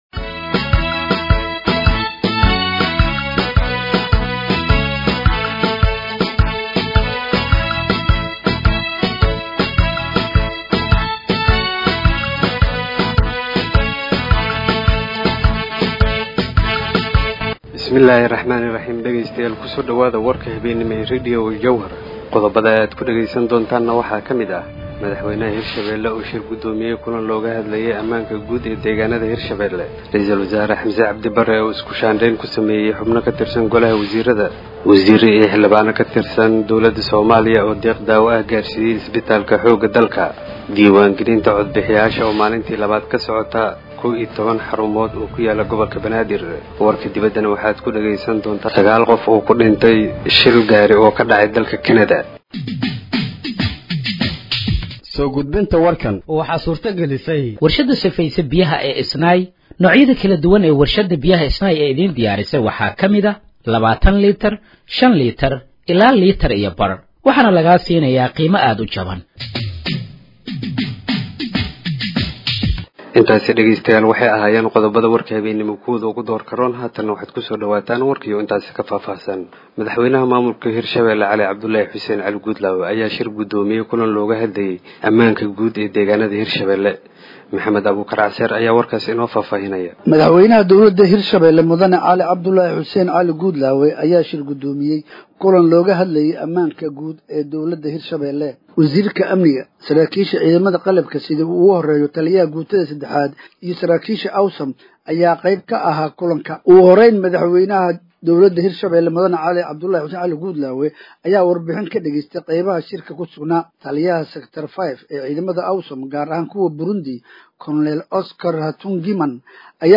Dhageeyso Warka Habeenimo ee Radiojowhar 27/04/2025